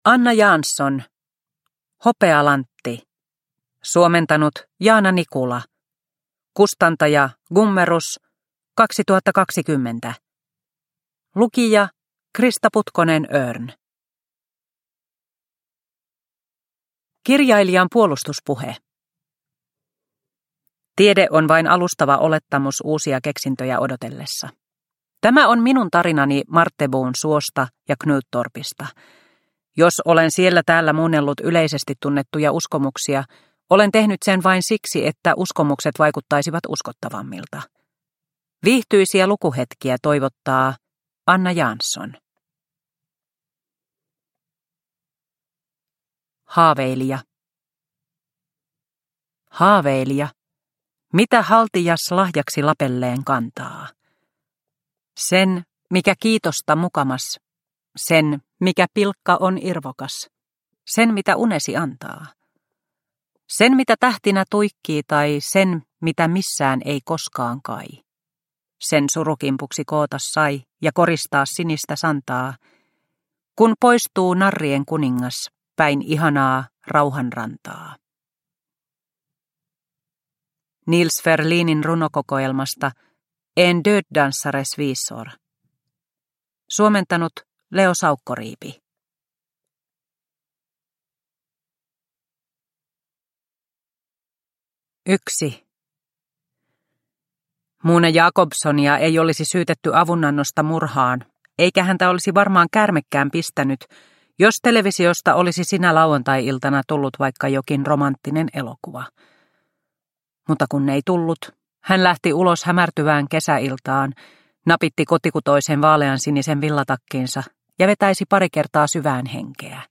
Hopealantti – Ljudbok – Laddas ner